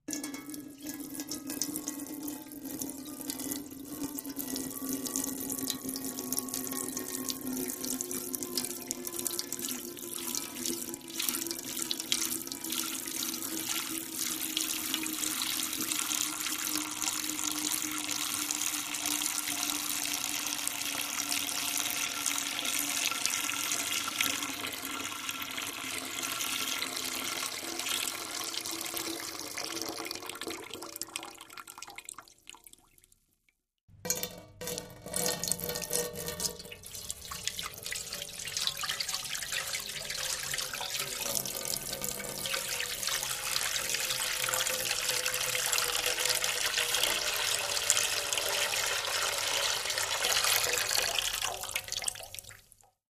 Water Trickle On A Metal Pail x2